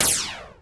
Shoot07.wav